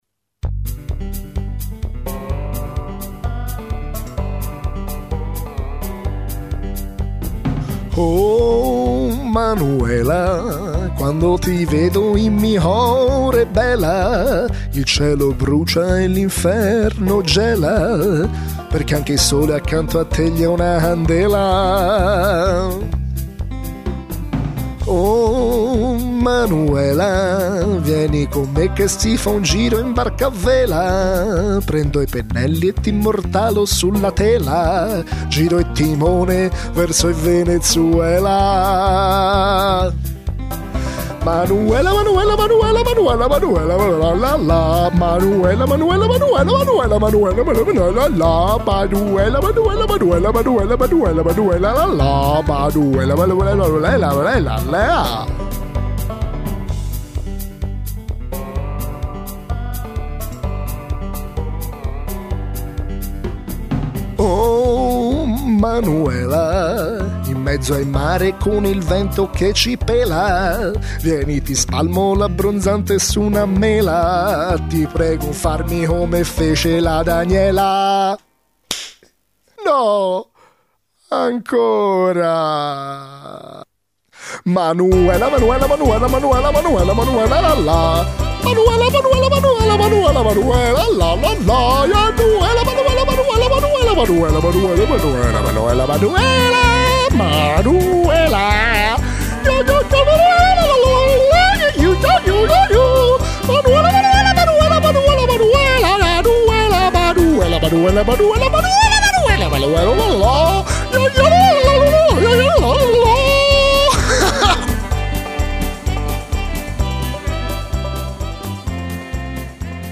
Un folle Yodel